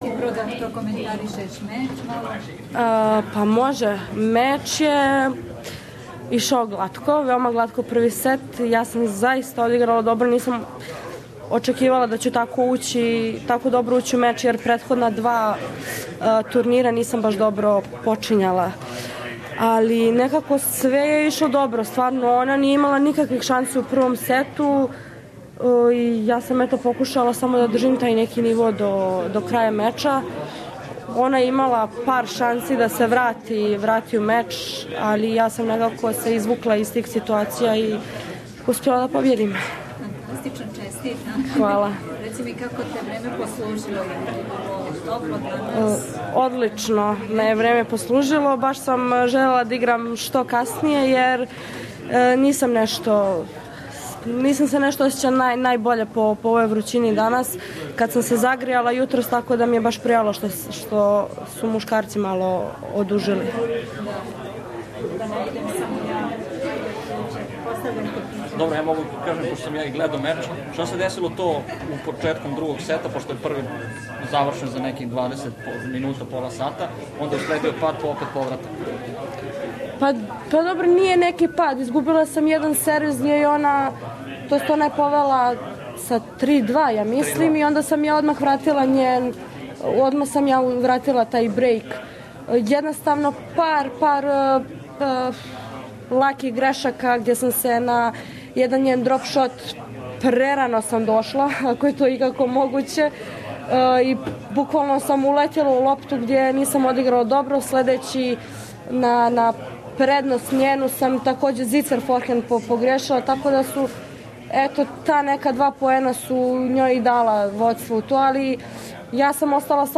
Danka Kovinic (Montenegro) beats Zheng Saisai (China) 6-0 6-4 i n the first round of Australian open on Tuesday. This is press conference in Serbian after the match.